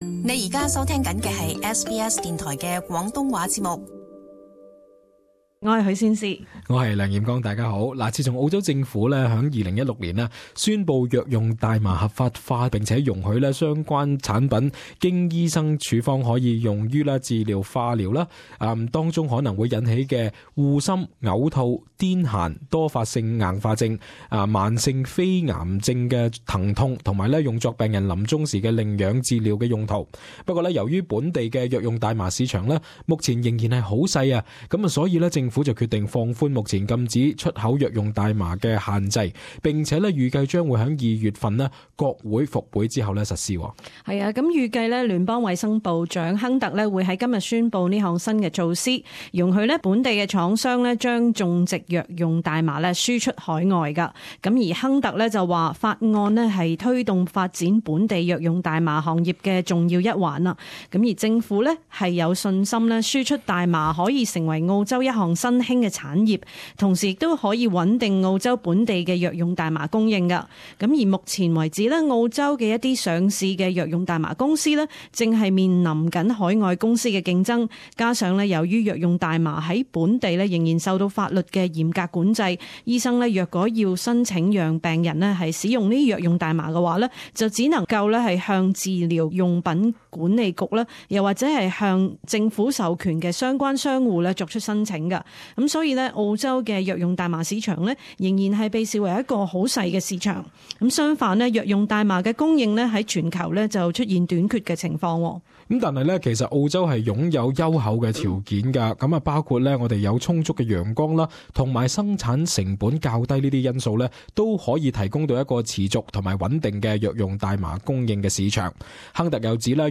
【時事報導】政府為出口藥用大麻開綠燈